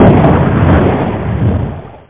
explos1.mp3